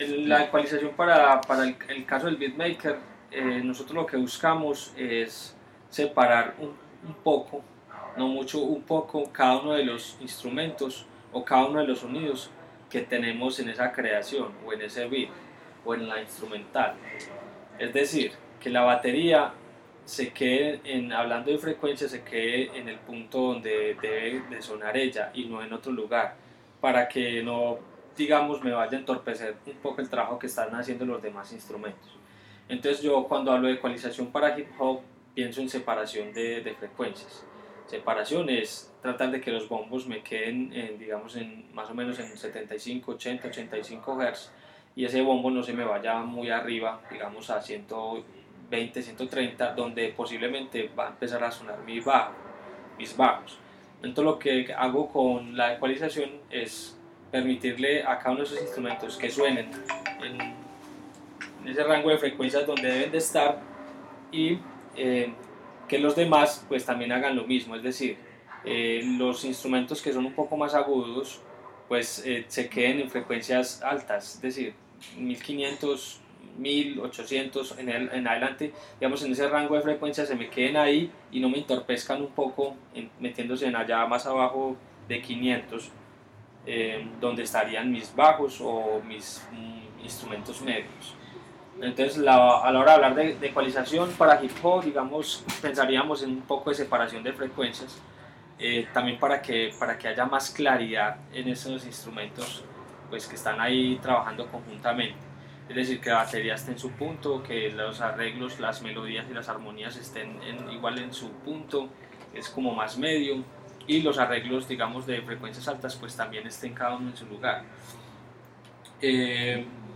Ecualizacion entrevista